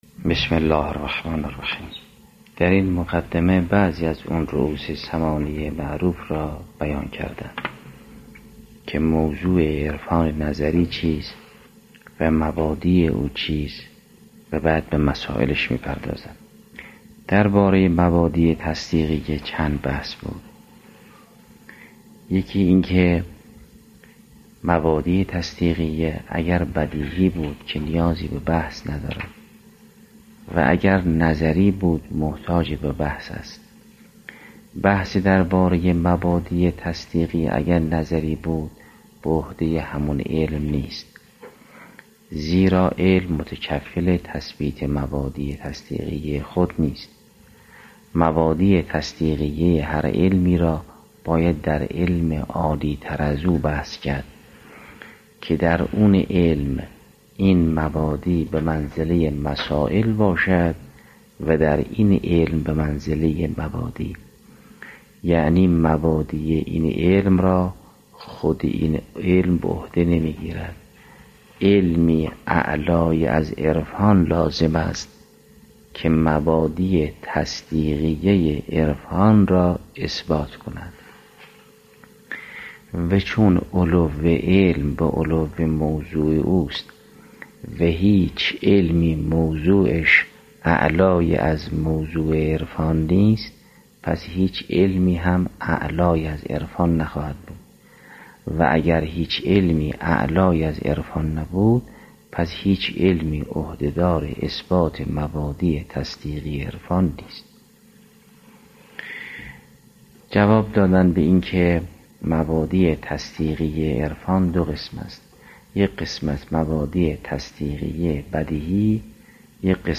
آيت الله جوادي آملي - تمهيد القواعد | مرجع دانلود دروس صوتی حوزه علمیه دفتر تبلیغات اسلامی قم- بیان